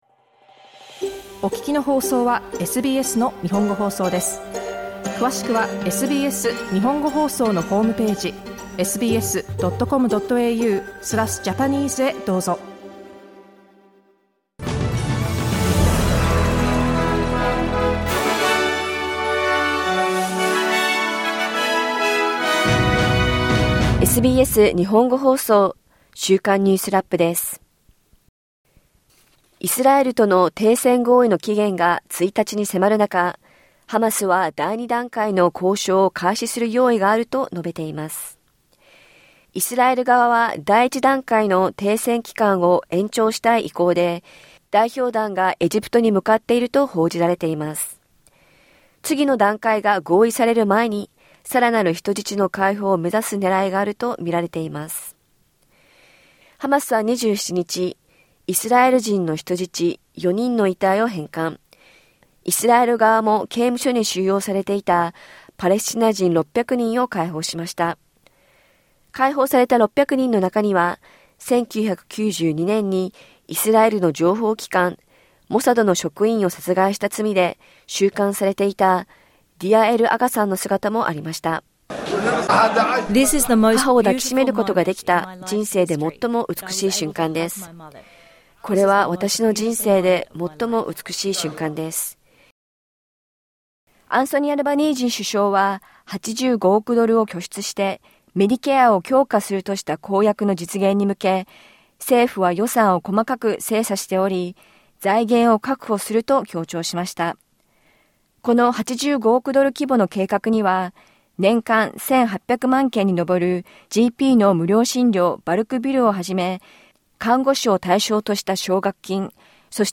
総選挙が迫る中、数十億ドル規模のメディケア公約の財源をめぐる議論が続いています。気象局はクイーンズランド州沿岸沖のサイクロンの動きを引き続き監視しています。1週間を振り返るニュースラップです。